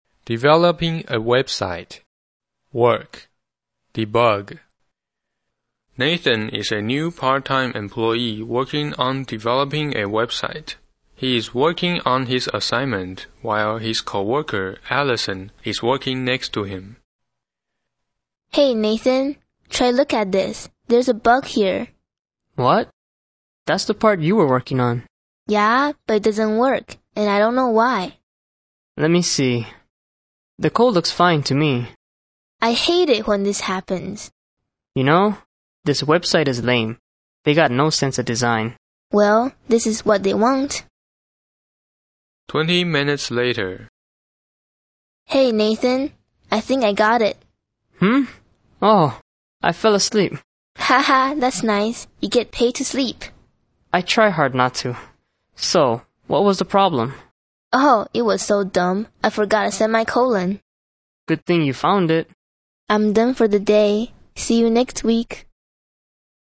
EPT美语 工作（对话） 听力文件下载—在线英语听力室